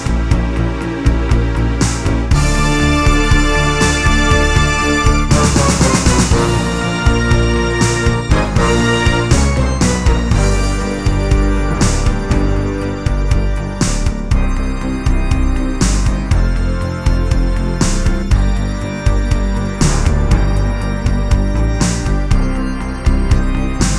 no Backing Vocals Musicals 4:19 Buy £1.50